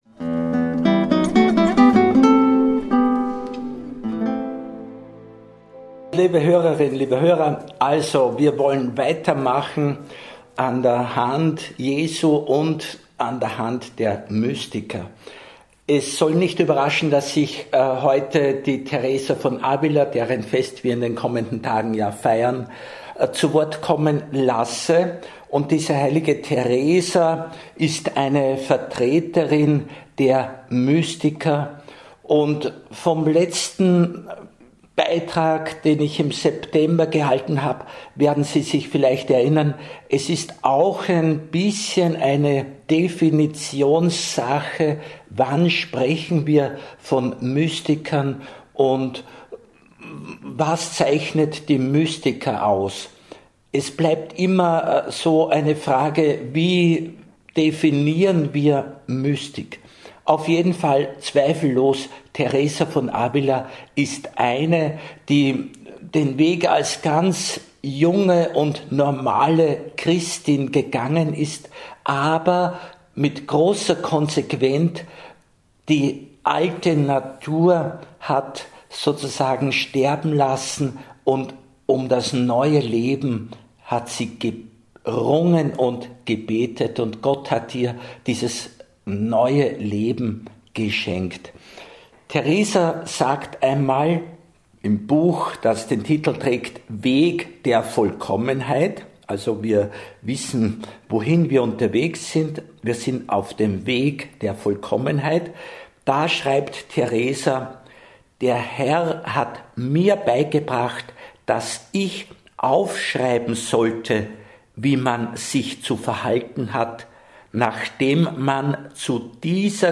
(Aufzeichnung der Radio Maria Sendung Mystik und Alltag Teil 2 vom 9.10.2024) Mehr